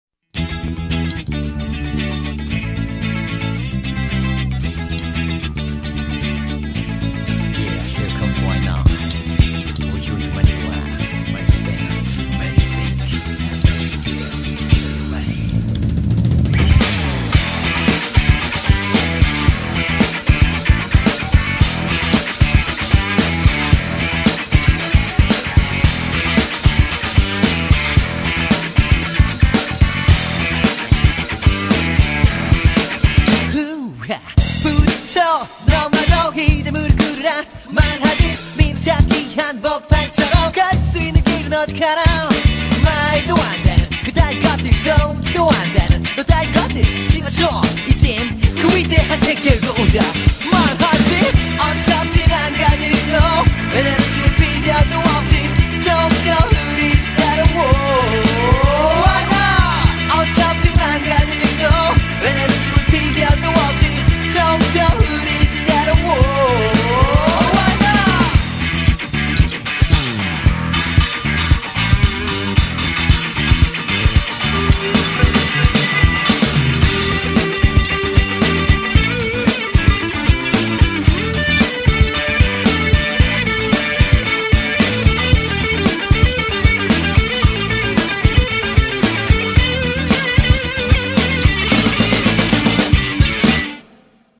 BPM113--1
- Music from custom cut